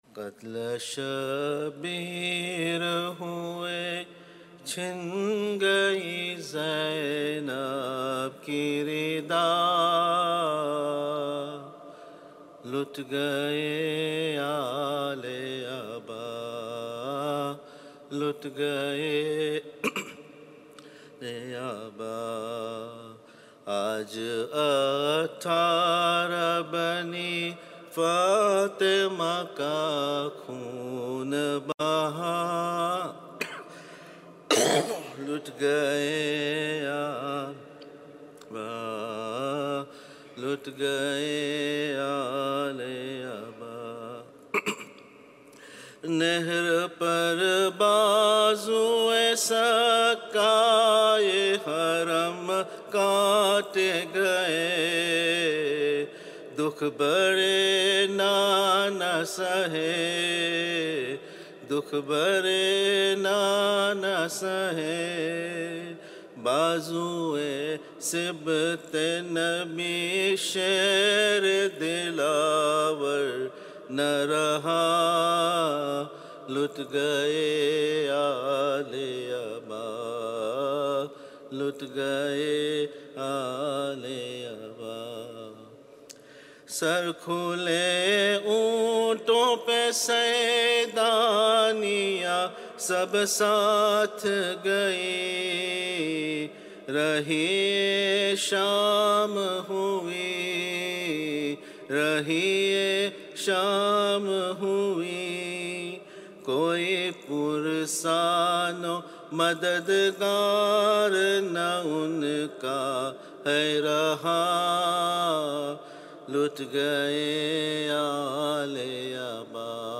Non Saff Calssic Marsia / Nawha